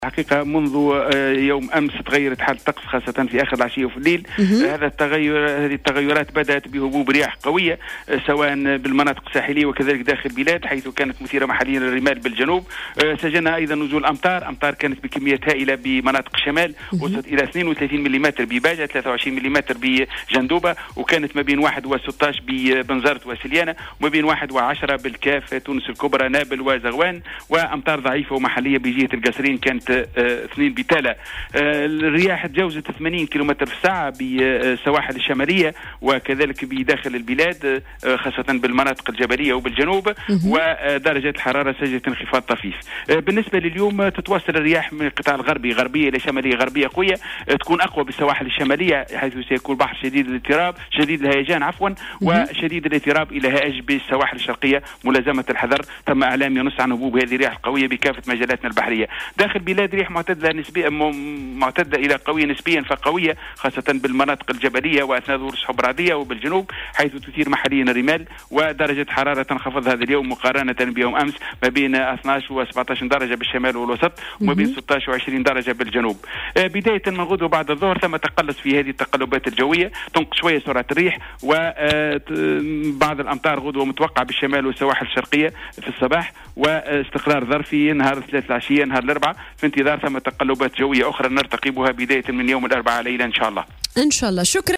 Intervenu ce matin sur les ondes de Jawhara FM